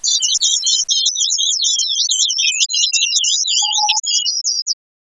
Hmm, nothing seems to be out of ordinary or resembling a pattern here.